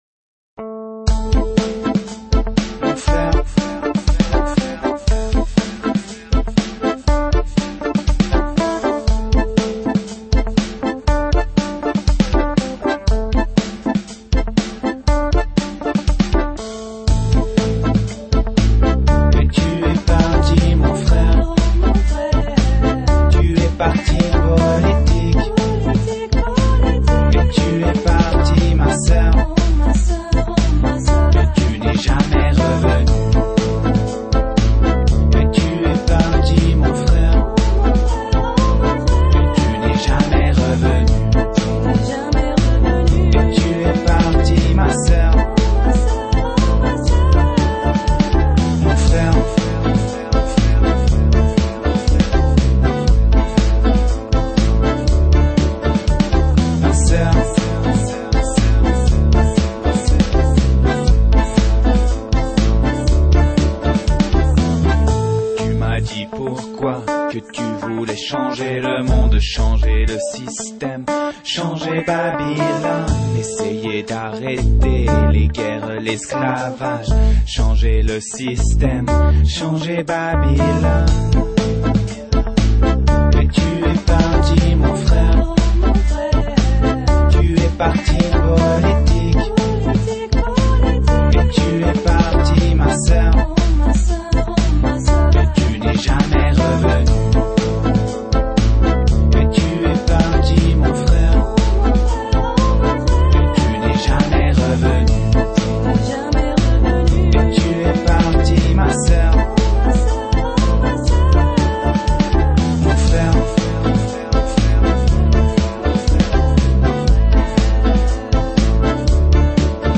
world
dub, reggae, hip hop and world music from the heart